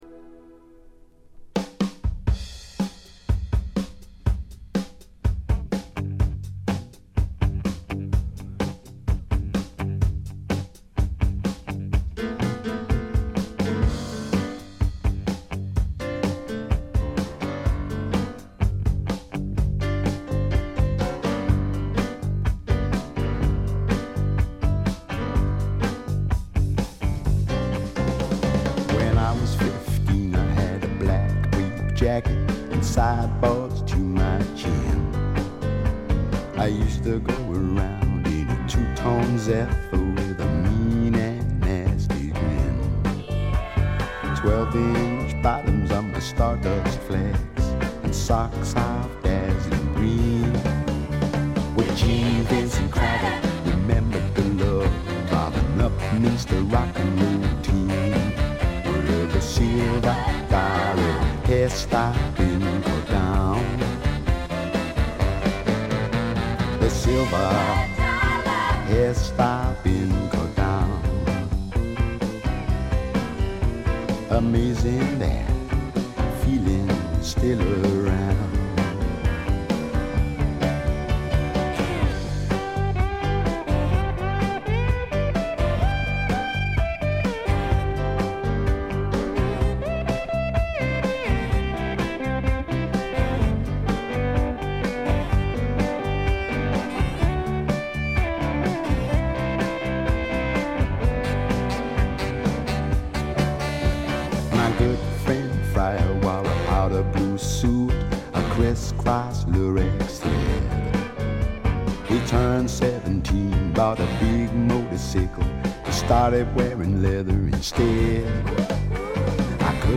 部分試聴ですがチリプチ少々。
後のブロックヘッズのような強烈な音もいいですが、本作のようなしゃれたパブロックも最高ですね！
試聴曲は現品からの取り込み音源です。